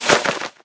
minecraft / sounds / mob / bat / takeoff.ogg
takeoff.ogg